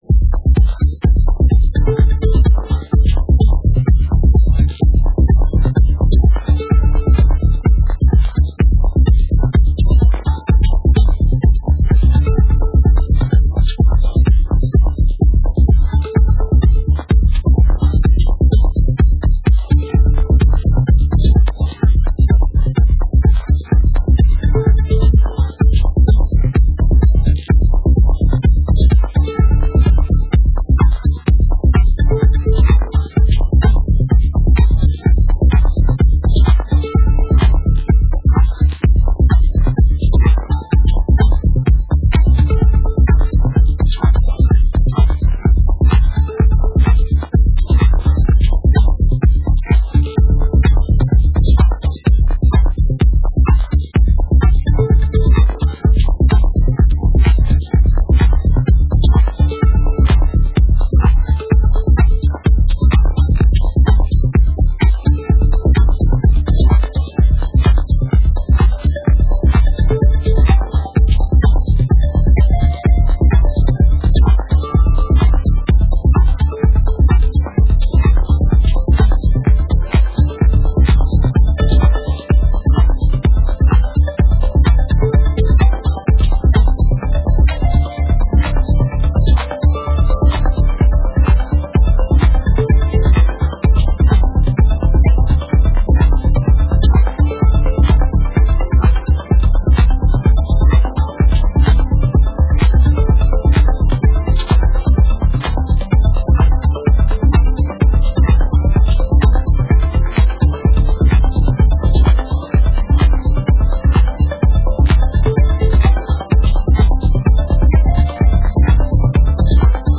deep & dubby vibes